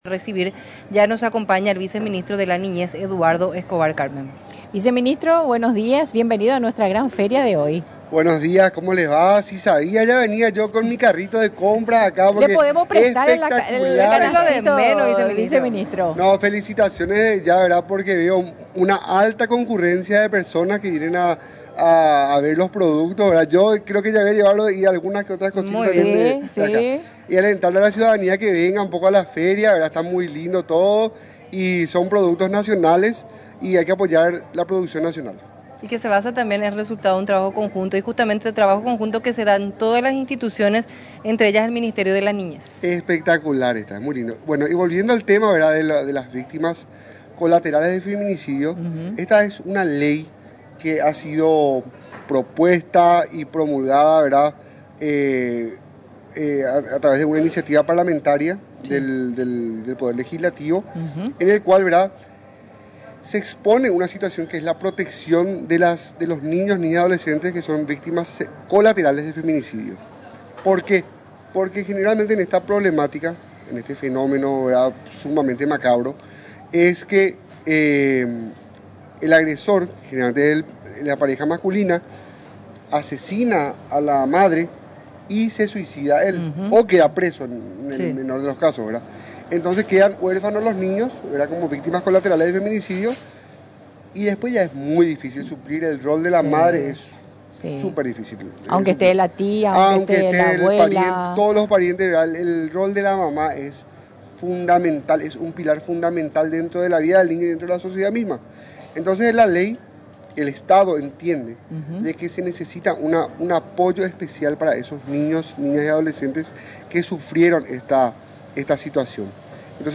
Mencionó durante la entrevista en la 920 AM y Paraguay TV, que en la mayoría de los casos, los niños quedan en el entorno familiar.